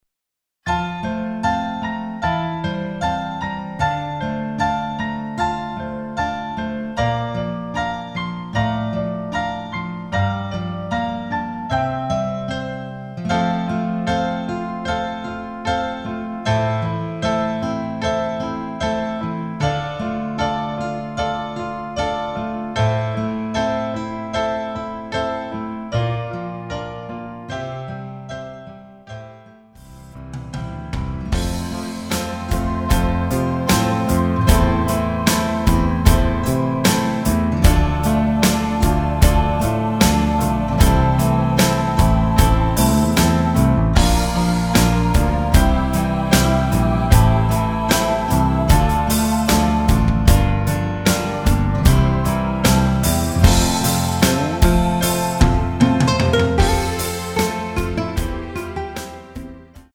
MR 입니다. 엔딩에 어쿠스틱 기타 부분이 너무 길고 페이드 아웃이라 조금 줄여서 엔딩을 만들었습니다.
키 F